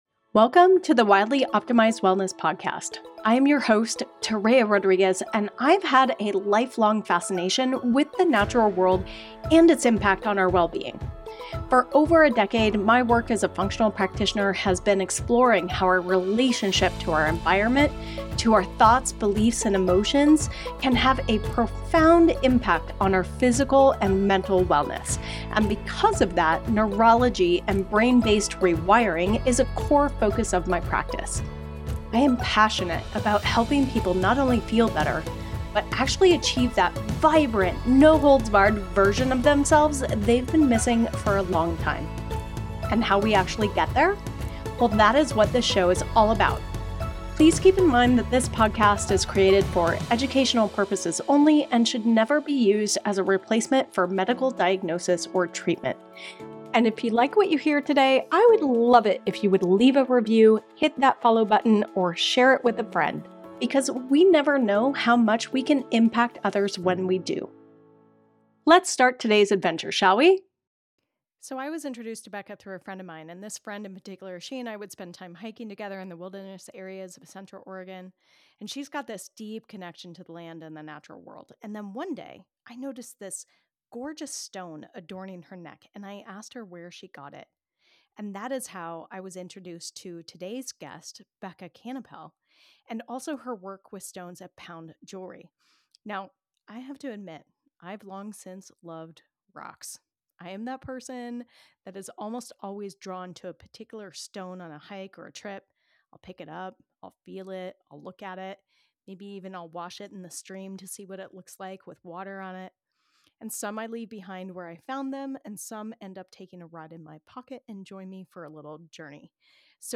Through insightful conversations, personal anecdotes, and expert interviews, she delves into the transformative power of neurology, brain rewiring, and intentional living.